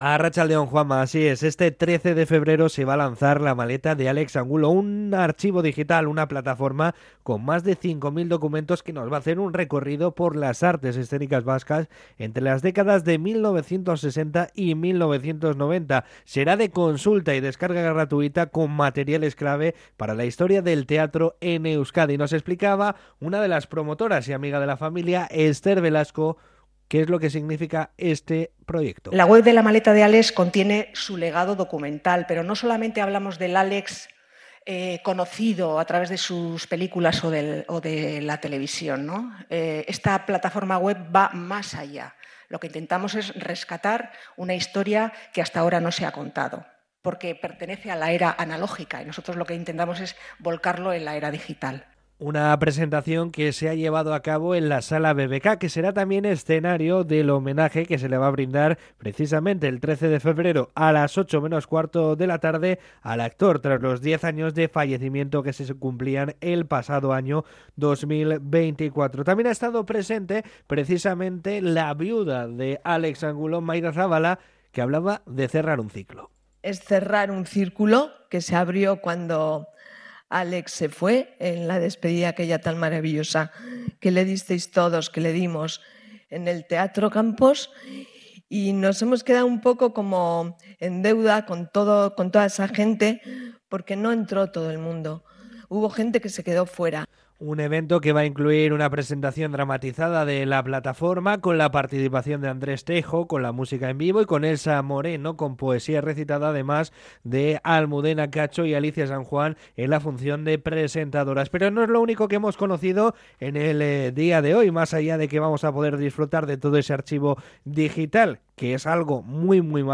19.-CRONICA-LA-MALETA-DE-ALEX-ANGULO.mp3